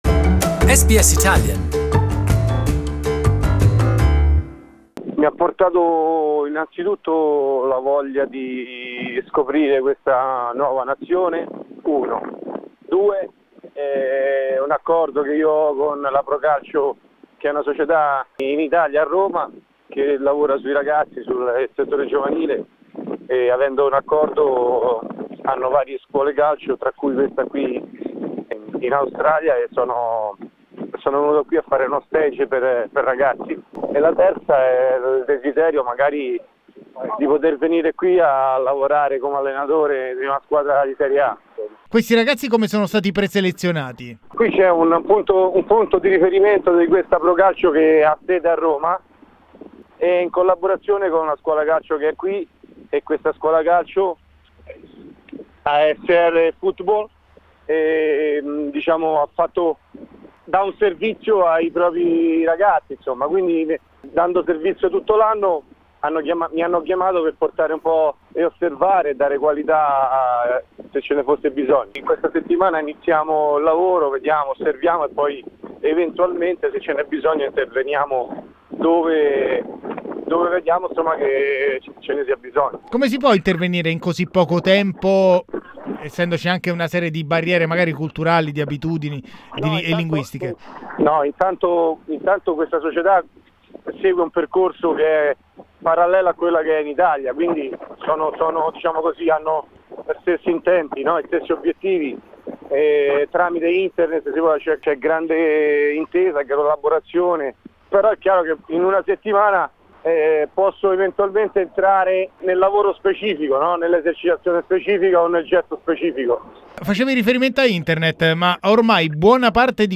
Giuseppe Giannini has just landed in Australia for the first time, but answers the phone sounding like he didn’t mind either the 24-hour flight or the different time zone.